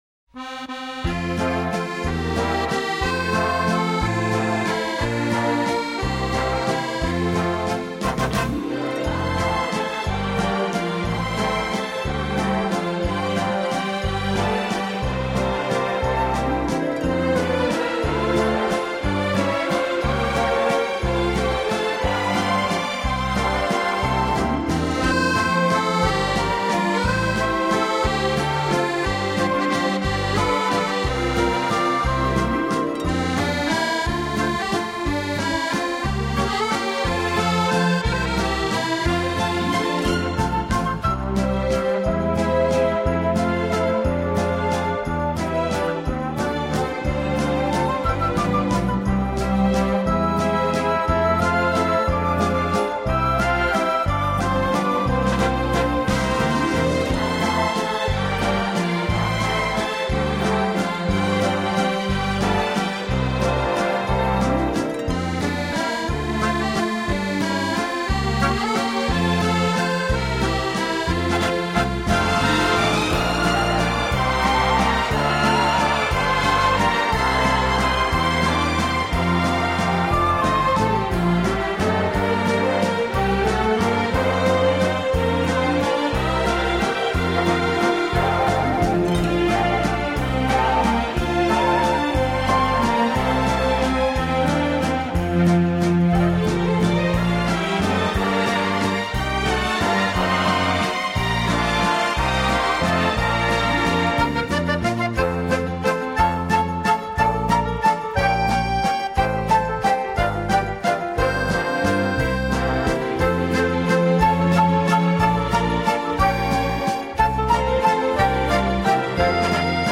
Genre:Easy listening